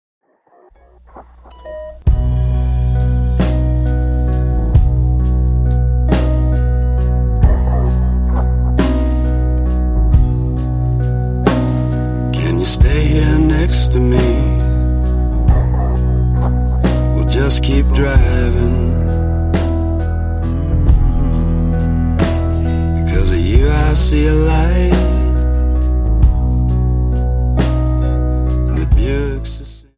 Indie / Alternativa